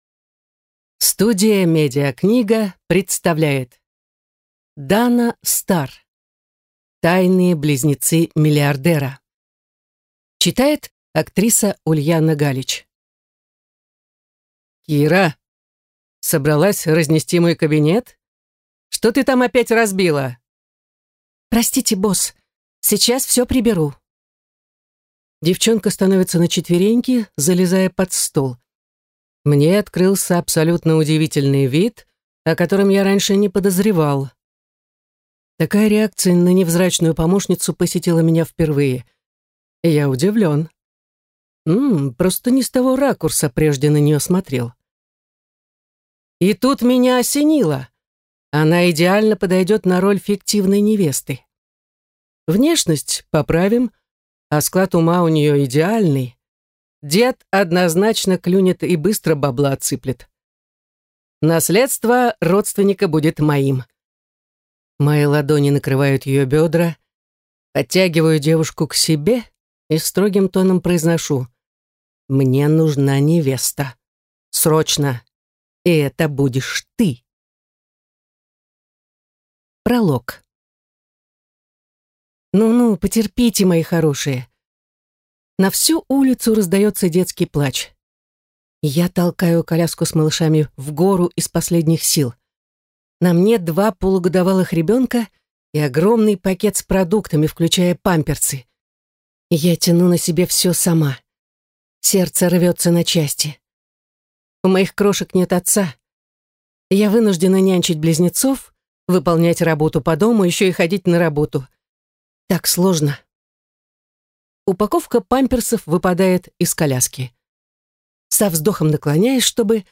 Аудиокнига Тайные близнецы миллиардера | Библиотека аудиокниг